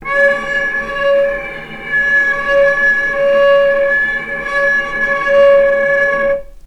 healing-soundscapes/Sound Banks/HSS_OP_Pack/Strings/cello/sul-ponticello/vc_sp-C#5-mf.AIF at cc6ab30615e60d4e43e538d957f445ea33b7fdfc - healing-soundscapes - Ligeti Zentrum Gitea
vc_sp-C#5-mf.AIF